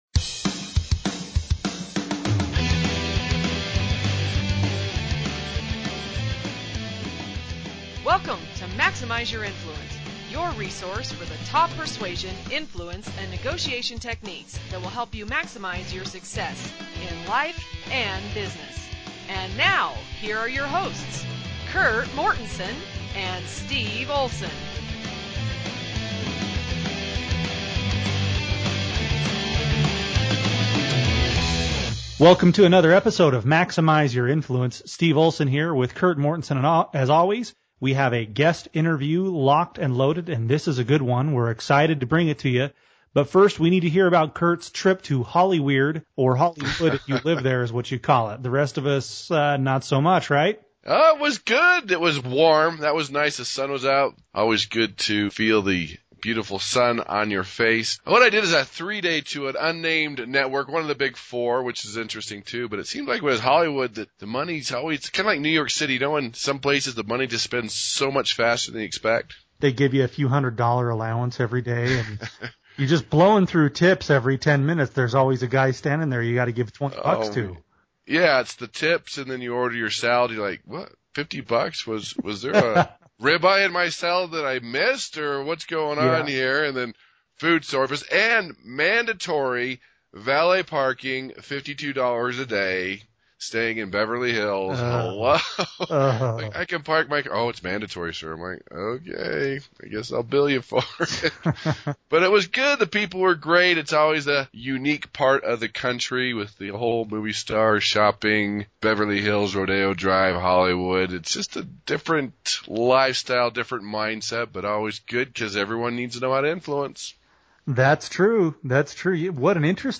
Episode 62 – Interview With John Lee Dumas